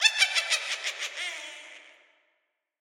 Звуки ведьмы
Зловещий хохот колдуньи